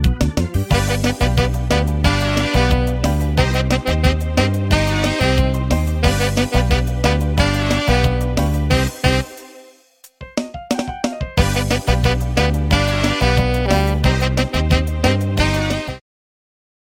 230 Простых мелодий для саксофониста